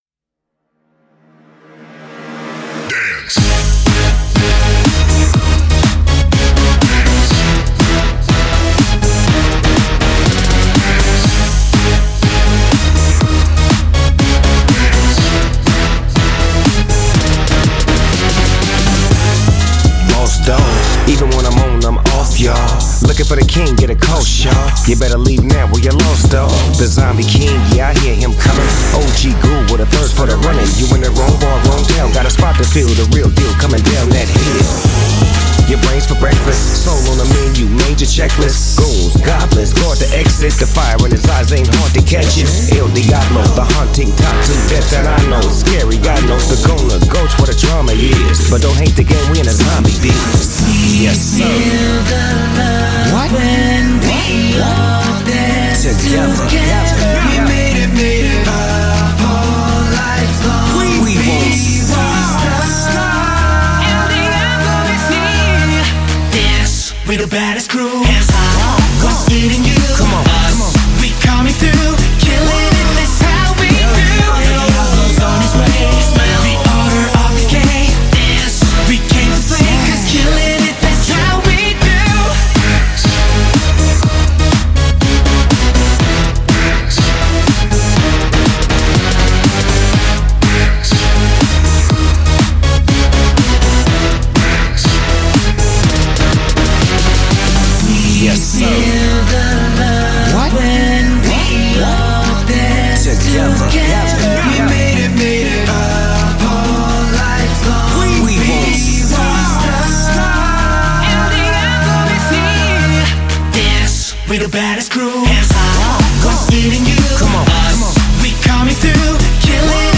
2021-03-05 📆 Genre: Rap, Dance 🎸 .....